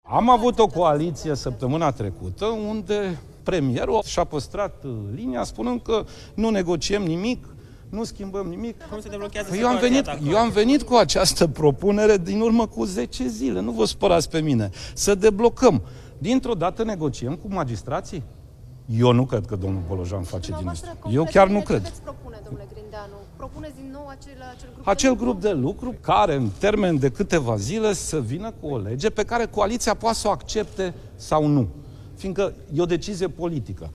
Liderul PSD Sorin Grindeanu: „Am avut o întâlnire săptămâna trecută, unde premierul și-a păstrat linia, spunând că nu negociem nimic”